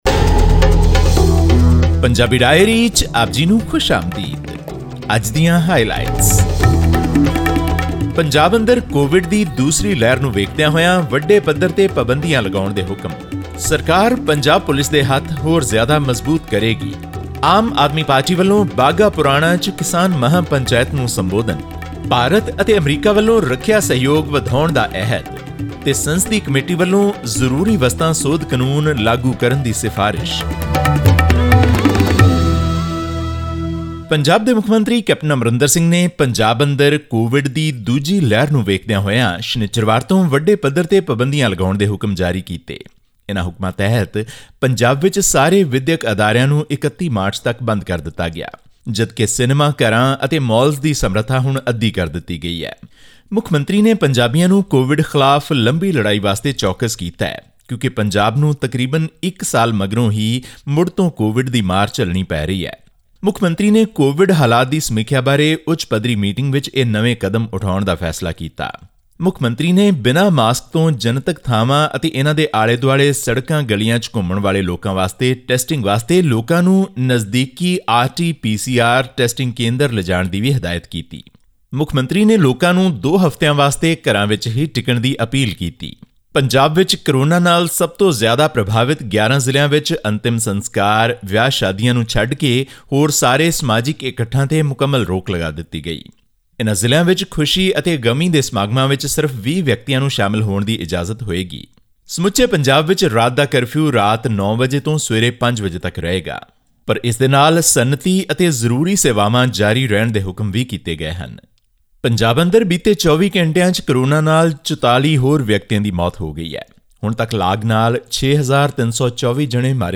Punjab Chief Minister Captain Amarinder Singh has announced new restrictions amid the rising cases of coronavirus in the state. This and more in our weekly news segment from Punjab.